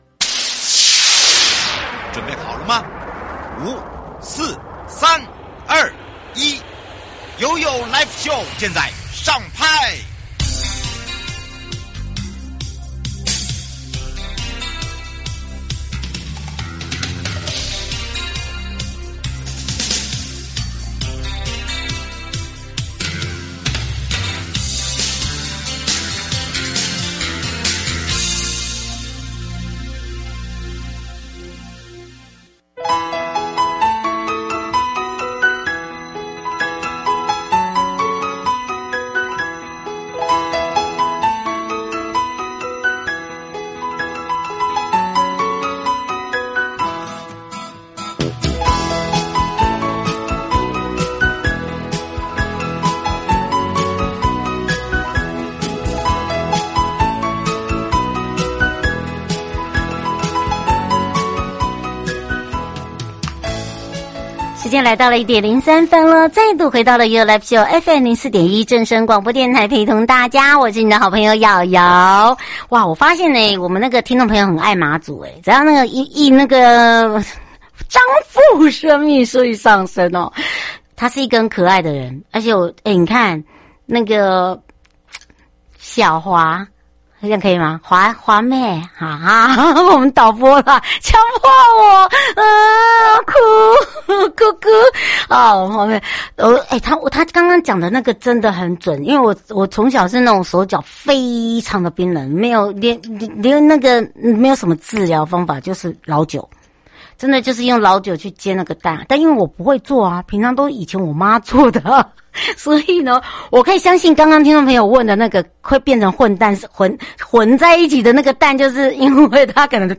受訪者： 台北地檢黃正雄主任檢察官 節目內容： 酒駕強制驗血違憲簡介(上集) 最近有一則有關大法官憲法訴訟第一